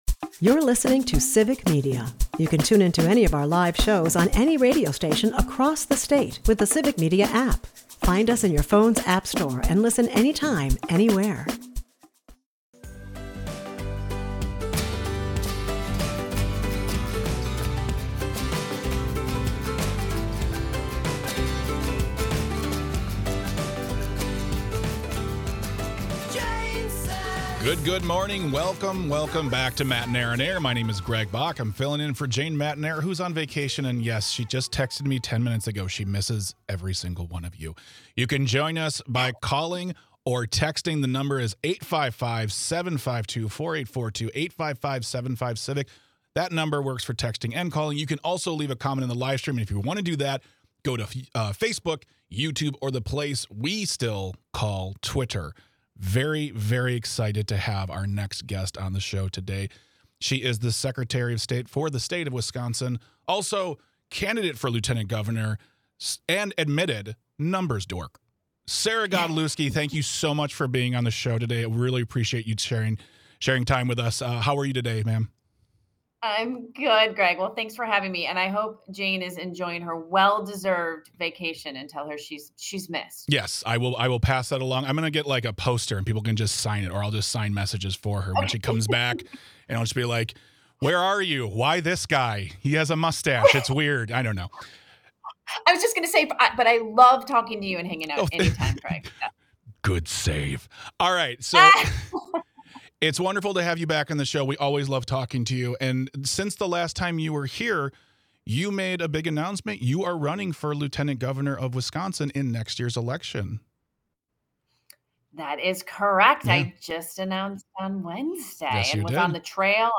Guests: Sarah Godlewski